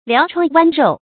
療瘡剜肉 注音： ㄌㄧㄠˊ ㄔㄨㄤ ㄨㄢ ㄖㄡˋ 讀音讀法： 意思解釋： 挖身上的肉來醫治傷口。